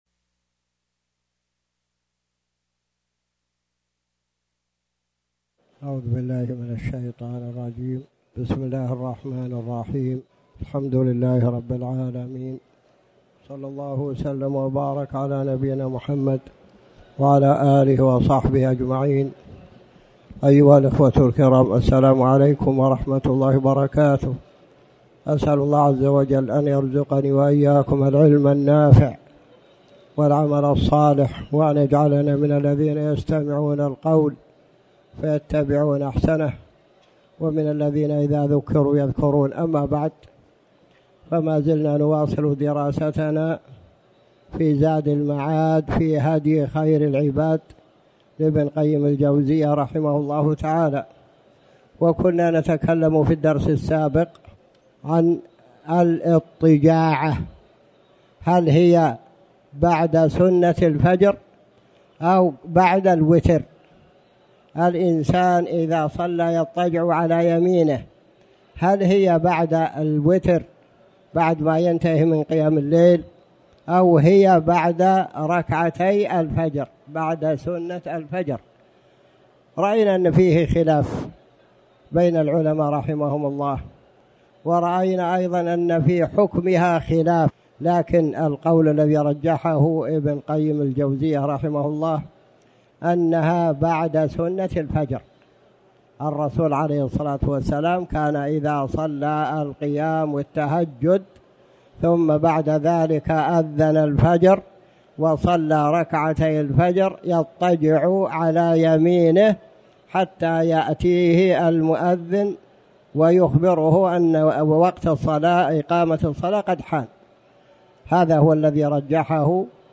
تاريخ النشر ١ شعبان ١٤٣٩ هـ المكان: المسجد الحرام الشيخ